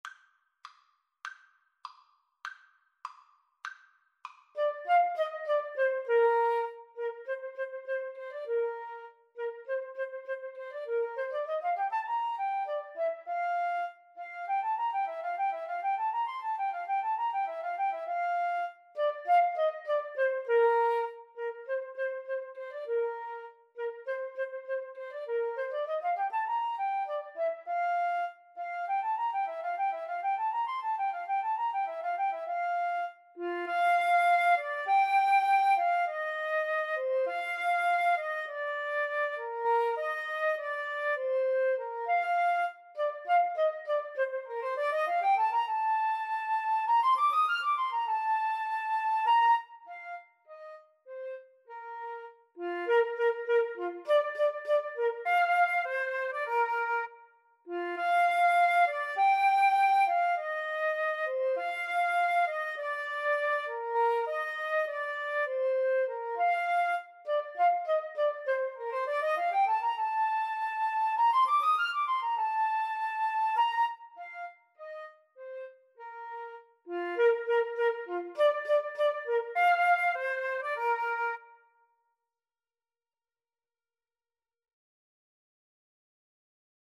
Free Sheet music for Flute-Saxophone Duet
Bb major (Sounding Pitch) (View more Bb major Music for Flute-Saxophone Duet )
2/4 (View more 2/4 Music)
Classical (View more Classical Flute-Saxophone Duet Music)